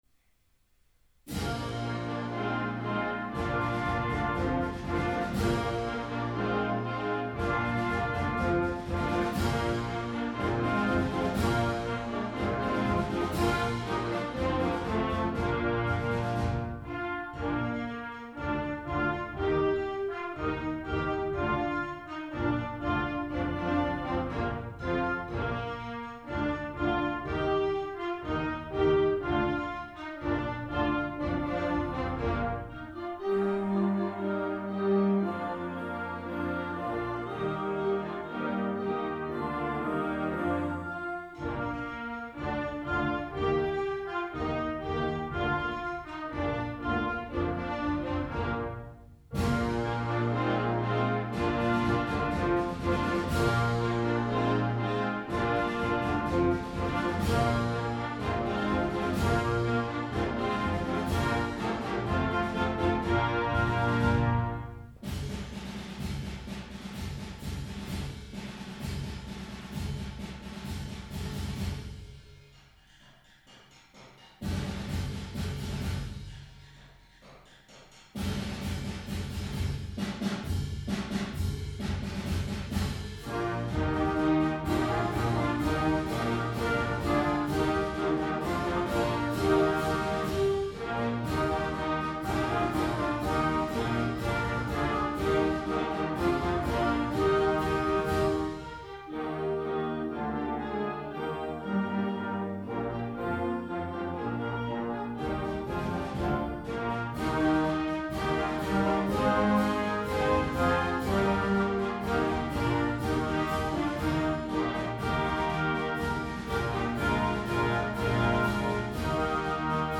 Genre: Band
Flute
Oboe
Bassoon
Clarinet in Bb 1-2
Alto Saxophone 1-2
Trumpet in Bb 1-2
Horn in F
Trombone
Tuba
Percussion (Snare Drum, Bass Drum)
Auxiliary Percussion (Crash Cymbals, Triangle)